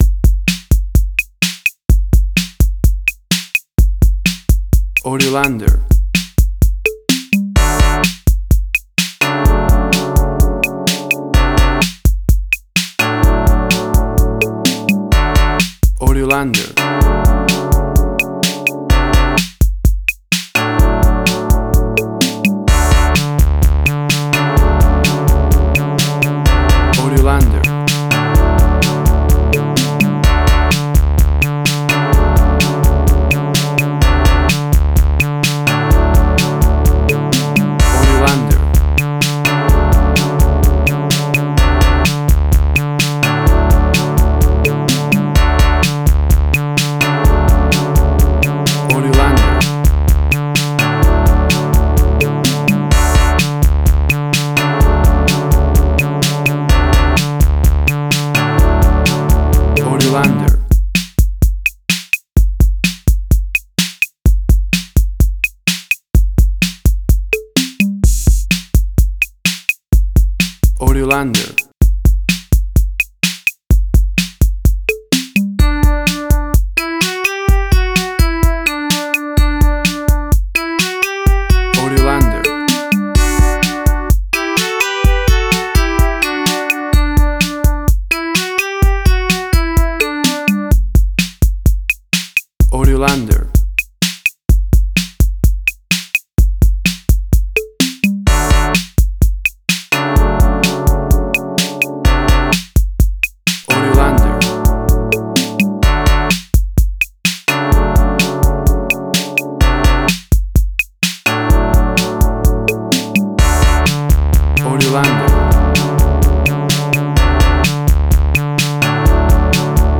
A cool explosion of classic 80s synth music!
Tempo (BPM): 128